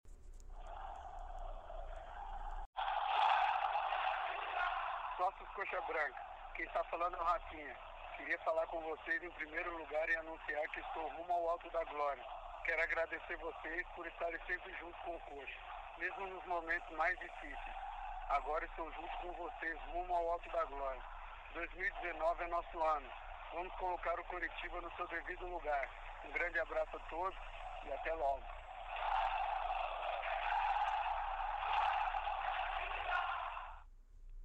Inclusive, o atleta gravou um áudio para os sócios do clube e prometeu que 2019 será um ano importante.